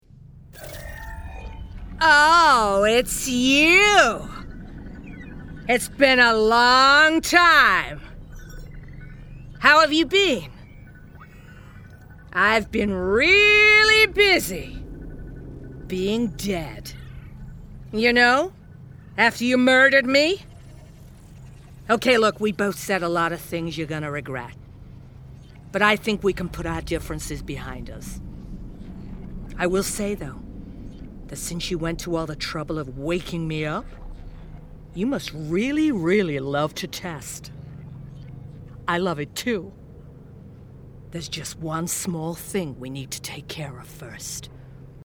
Computer Villain – American accent